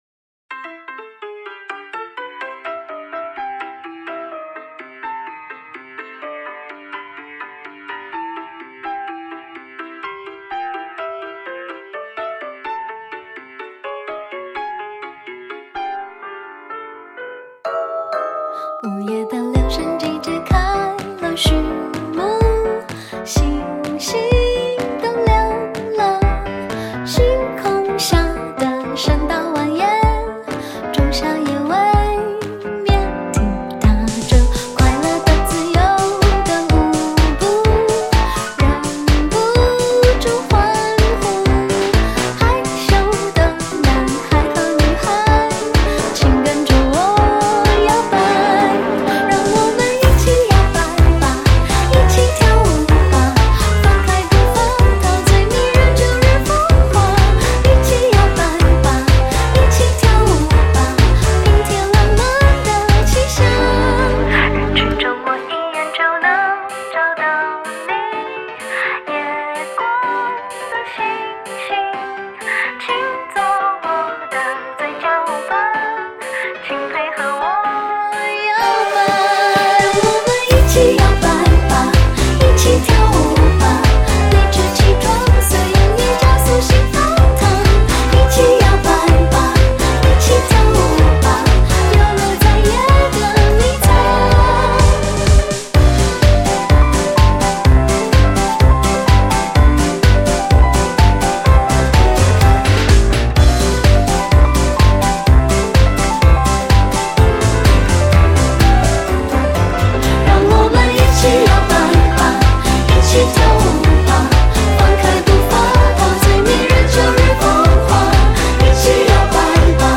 前卫复古混搭创造属于自己语言的摇摆乐